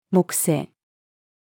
木星-female.mp3